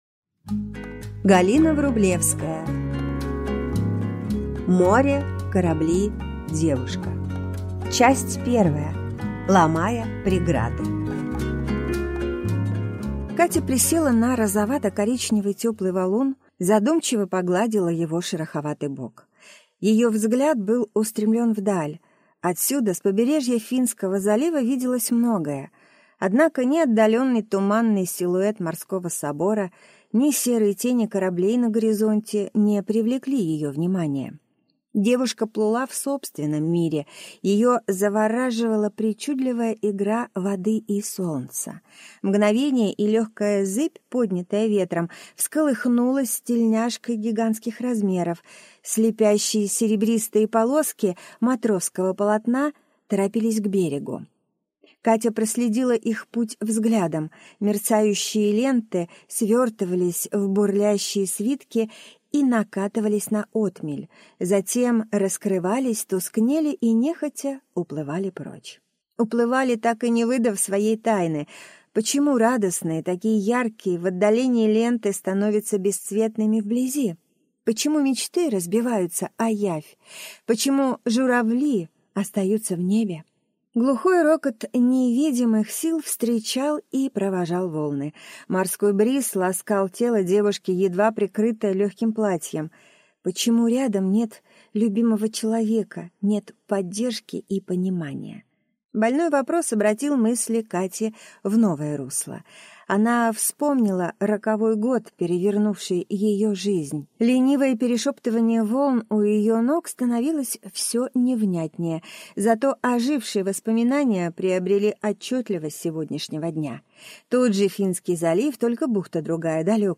Аудиокнига Море. Корабли. Девушка | Библиотека аудиокниг